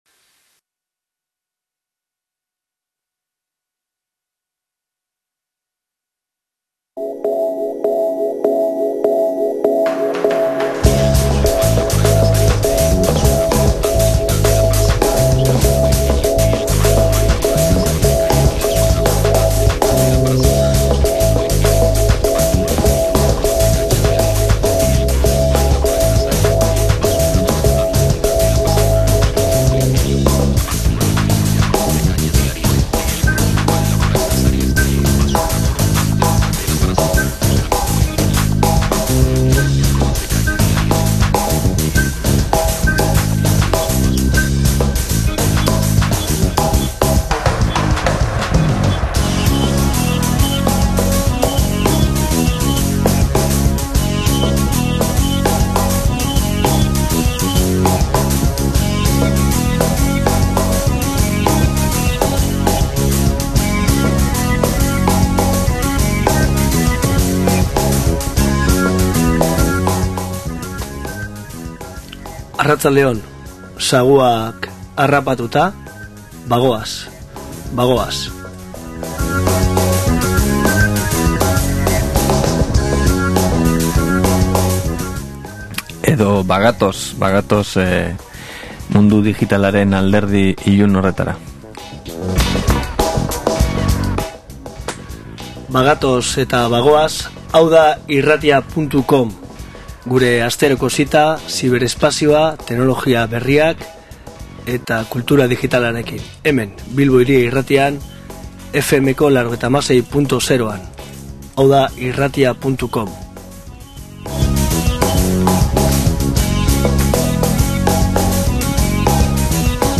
Azkenik lortu dugu saioa ondo grabatzea, mp3ra pasatu ahal izateko.